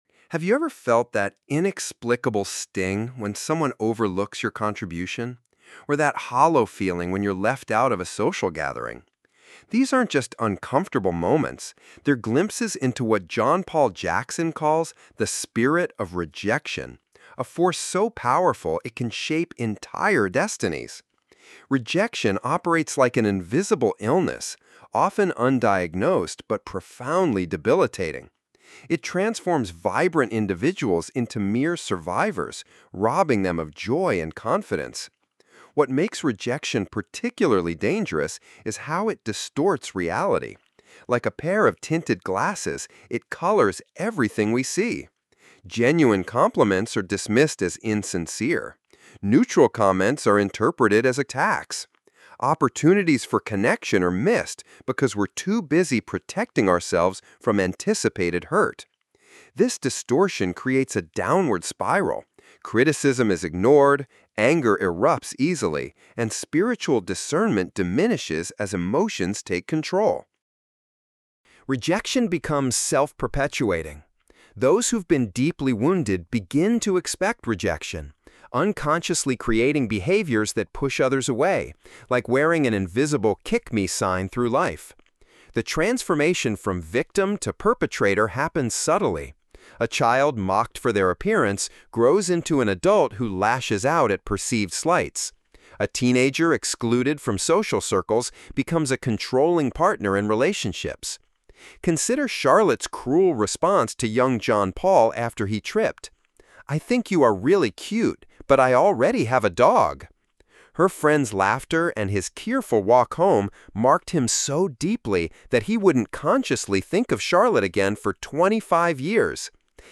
Read or listen to the summary of Breaking Free of Rejection by John Paul Jackson.